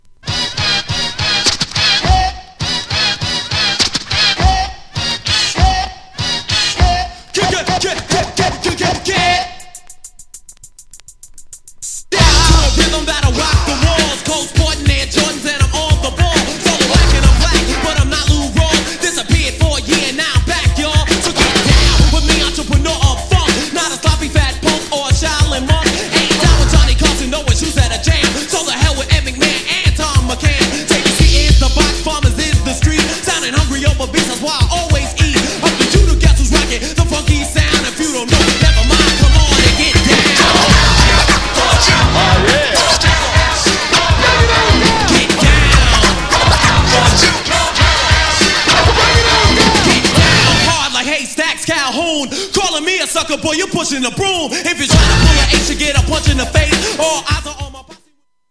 アグレッシブなフロウが最高にカッコイイ!!
GENRE Hip Hop
BPM 101〜105BPM